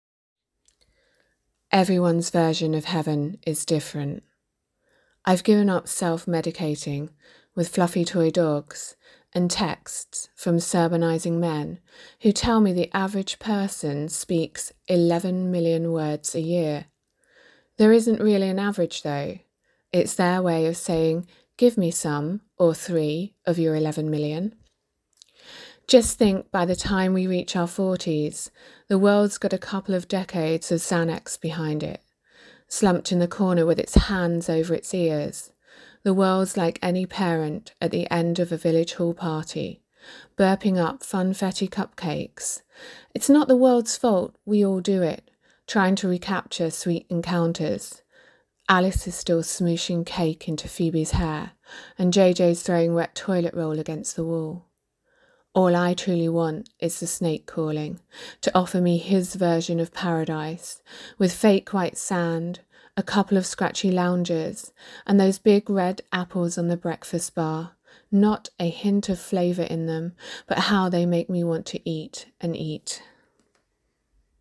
As an added bonus we are (or soon will be) featuring audio recordings of their submitted poems by the poets themselves.